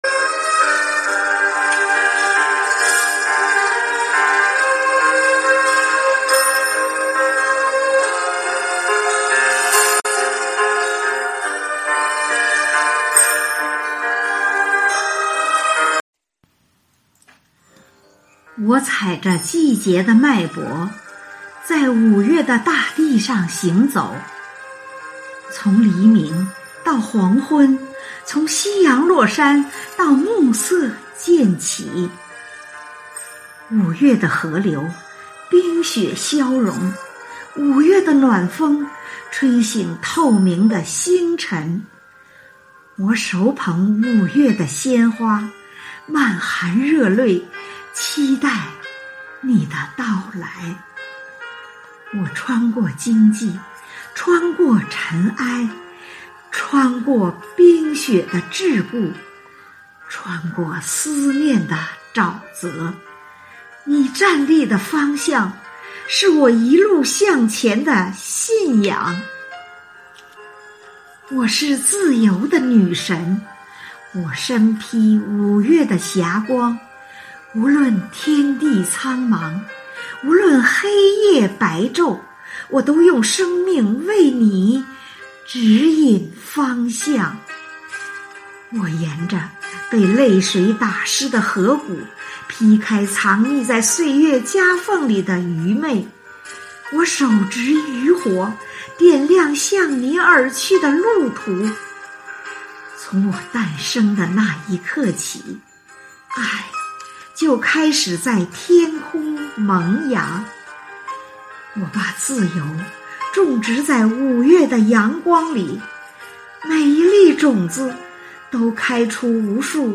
生活好课堂幸福志愿者中国钢研朗读服务（支）队第十一次云朗诵会在五月开启，声声朗诵、篇篇诗稿赞颂红五月，讴歌美好生活，吟诵美丽中国。
《五月的鲜花》朗诵